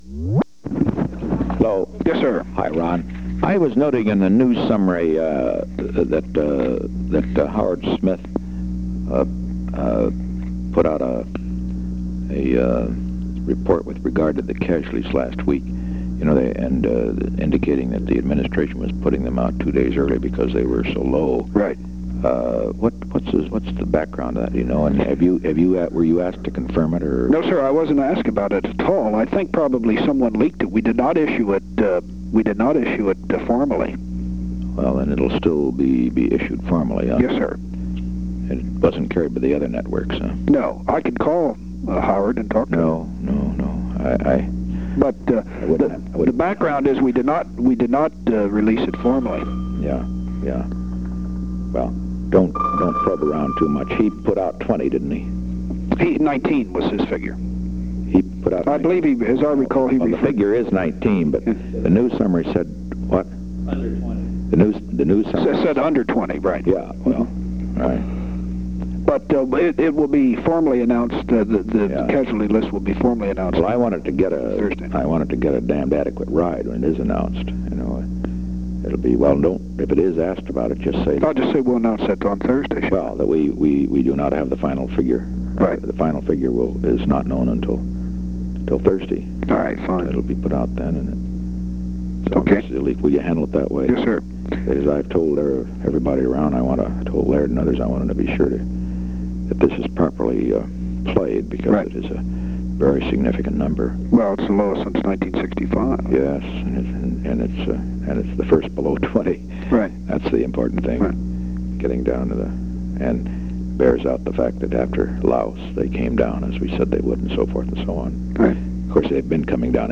Location: White House Telephone
The President talked with Ronald L. Ziegler; H.R. (“Bob”) Haldeman can be heard in the